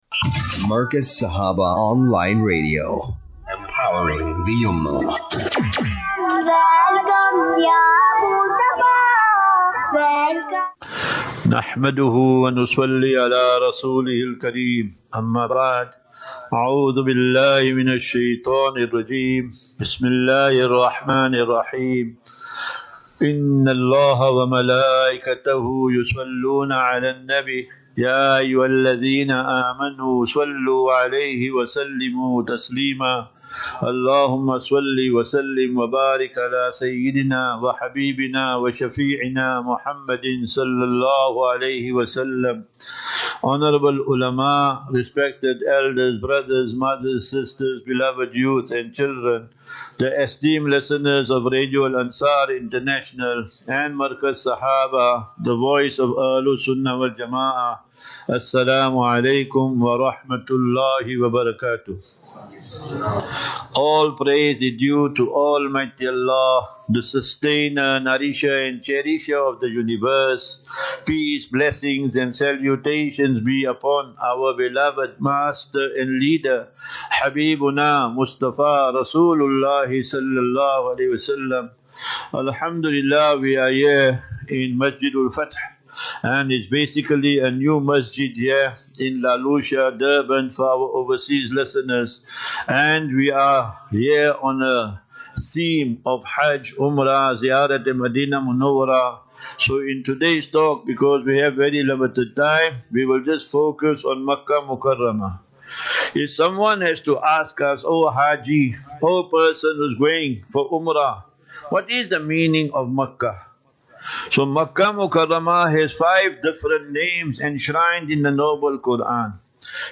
11 Apr 11 April 25 - Jumu'ah Lecture at Masjid Ul Fatah (Umhlanga)
Lectures